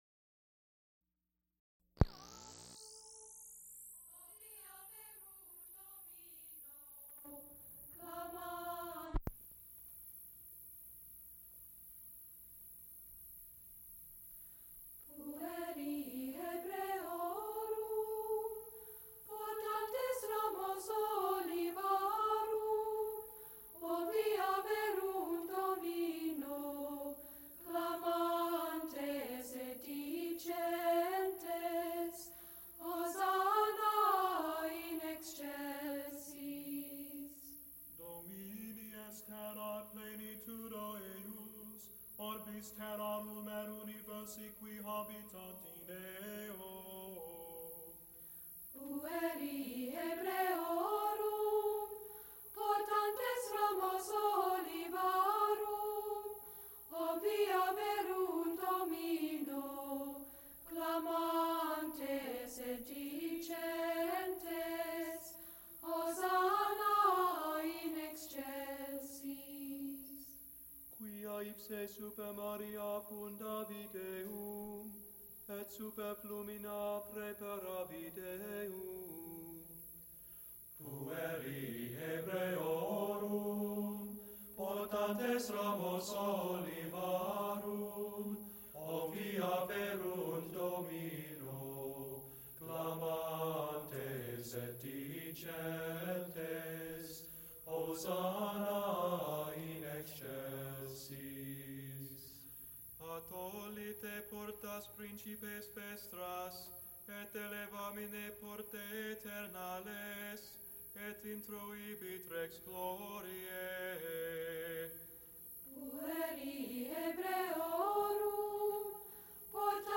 Multiple false starts on each tape.
Recorded live January 9, 1976, Heinz Chapel, University of Pittsburgh.
Madrigals
Extent 3 audiotape reels : analog, half track, stereo, 7 1/2 ips ; 7 in.
Choruses, Sacred (Mixed voices) with orchestra
Gregorian chants